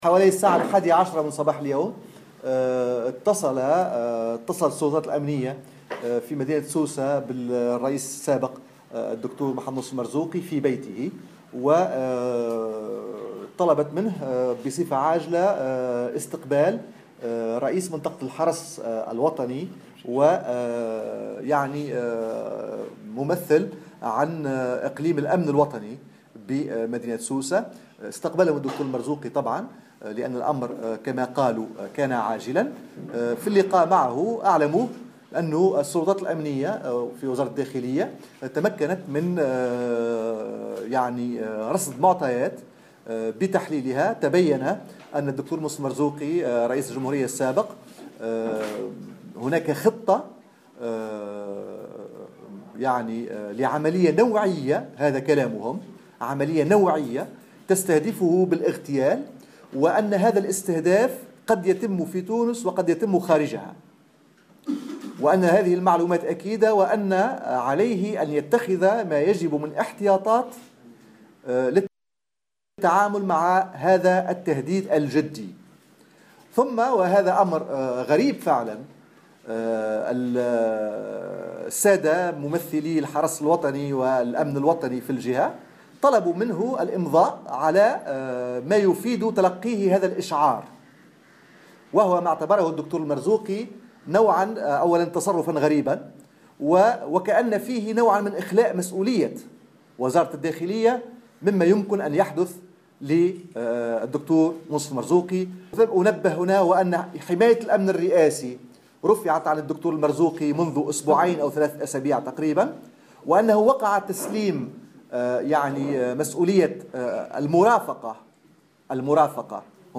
خلال ندوة صحفية انعقدت عشية اليوم